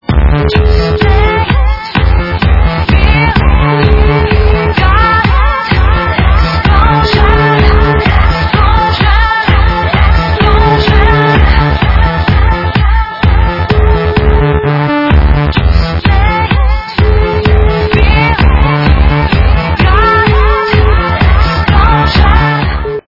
- Remix
качество понижено и присутствуют гудки